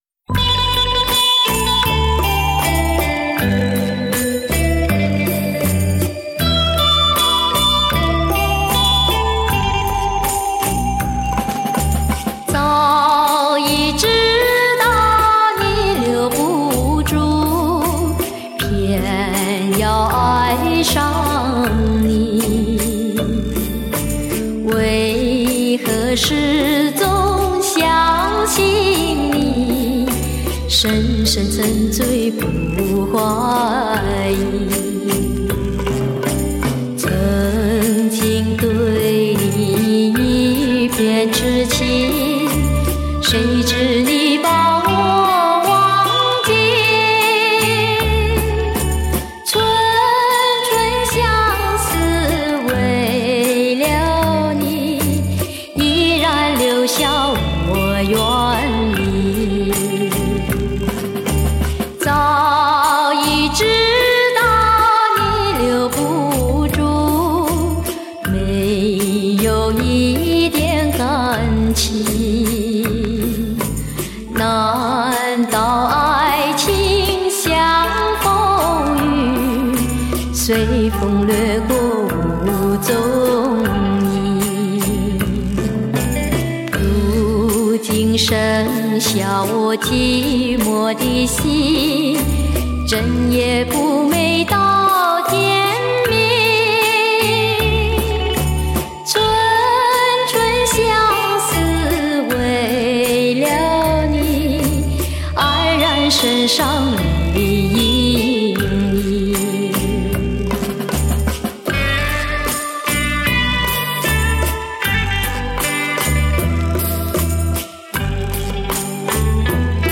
低沉、富有磁性的歌声